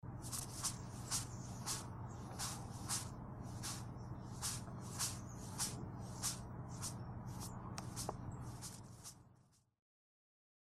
Звук шуршания богомола своим телом